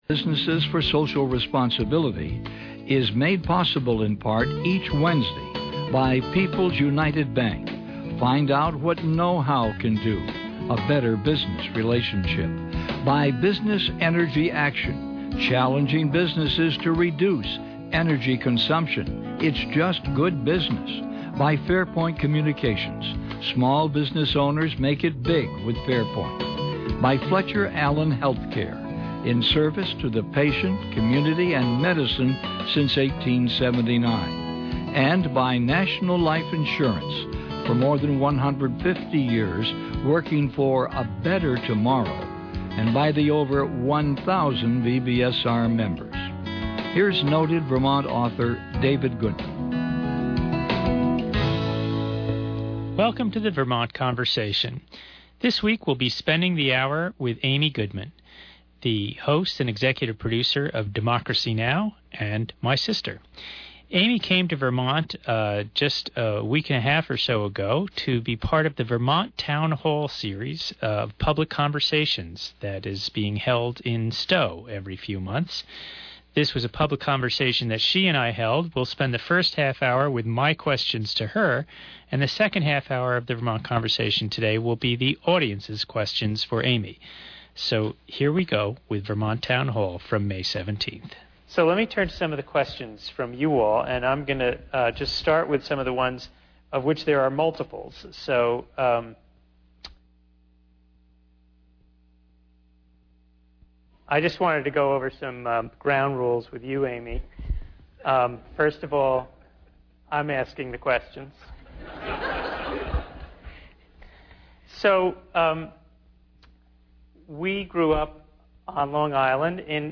This is a broadcast of a Vermont Town Hall public conversation held on May 17, 2014 at Spruce Peak Performing Arts Center in Stowe, Vermont.